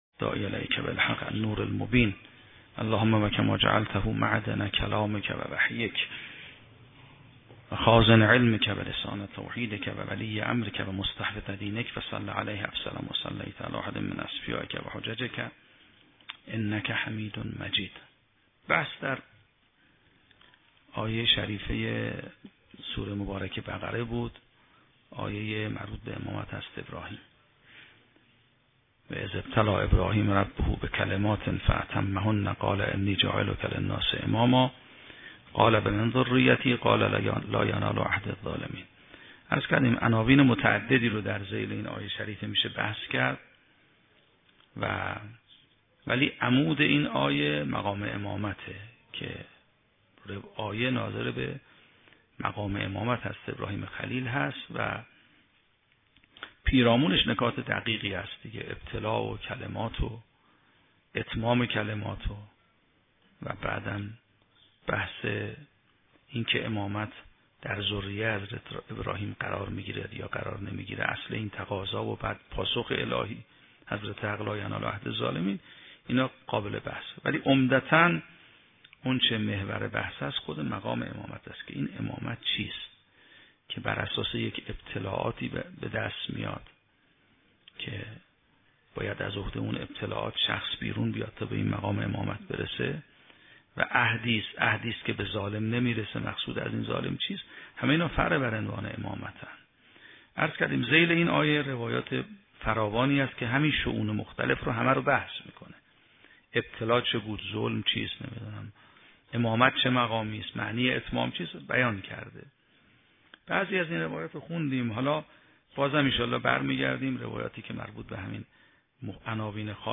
شرح و بررسی کتاب الحجه کافی توسط آیت الله سید محمدمهدی میرباقری به همراه متن سخنرانی ؛ این بخش : بررسی مقام امامت در روایات و بحث مفترض الطاعه بودن